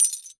GLASS_Fragment_10_mono.wav